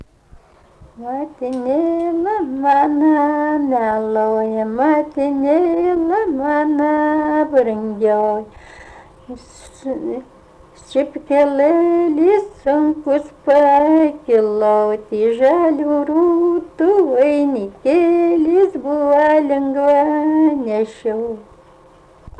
rauda